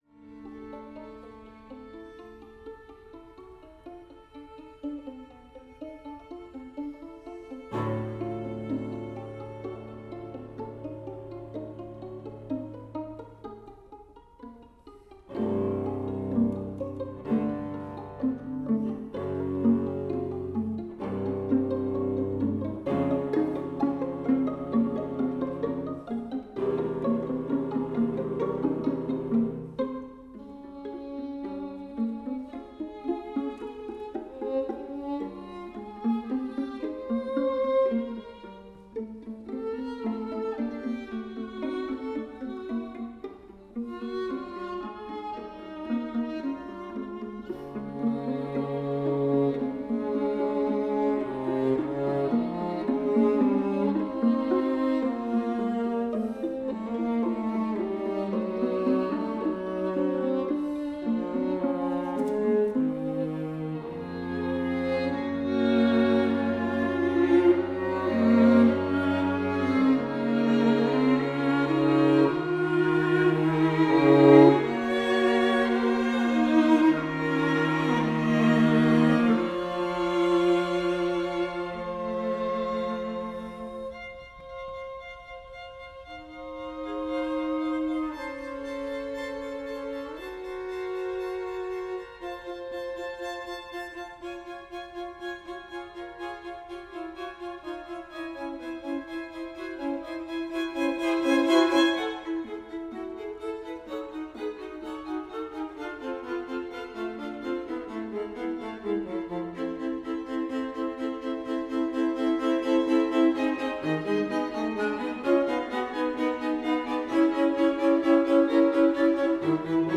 String Quartet no. 1 - Third Movement
violin
viola
cello